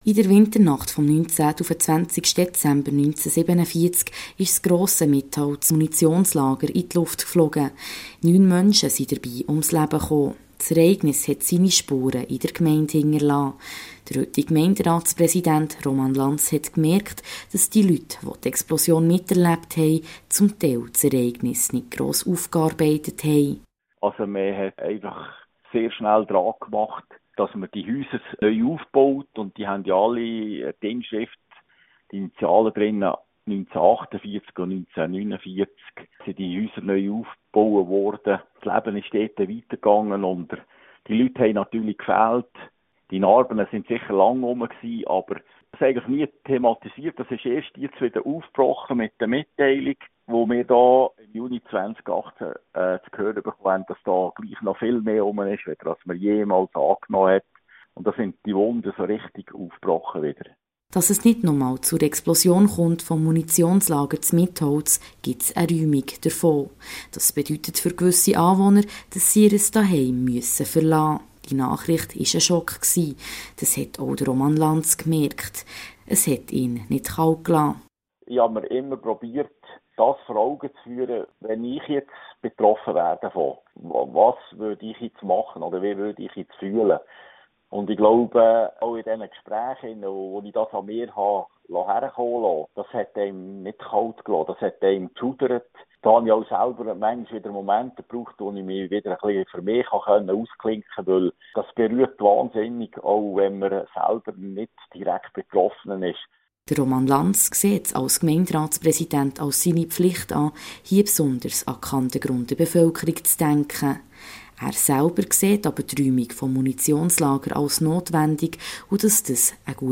Der Gemeindepräsident der Gemeinde Mitholz erzählt, wie es den Mitholzer*innen so geht mit der Vergangenheit, aber auch mit der aktuellen Situation.